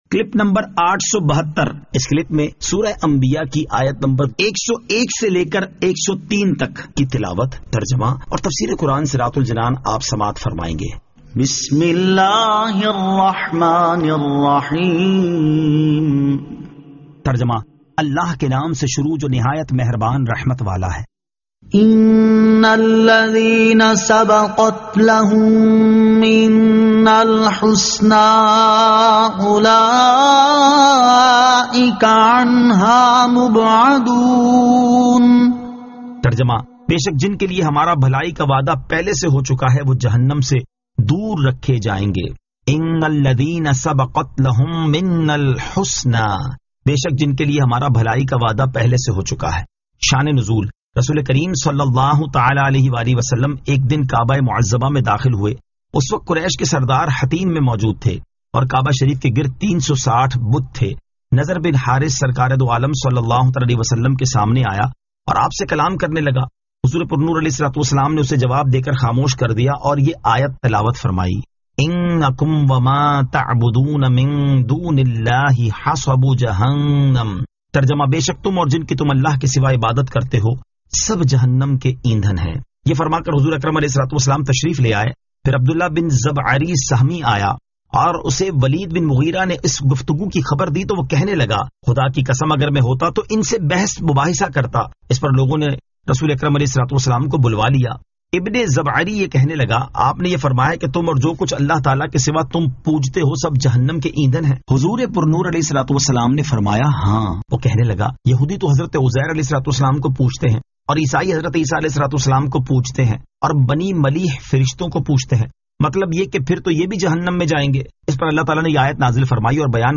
Surah Al-Anbiya 101 To 103 Tilawat , Tarjama , Tafseer